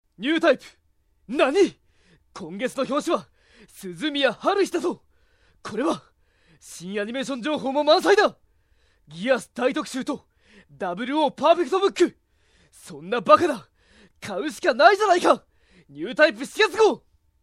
声真似奥義演習場~その弐~
声マネに関しては似てるモン似てねぇモン多々あります。
雑誌「ニュータイプ」のCM。